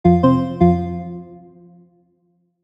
Notification Interface Success Positive Corrects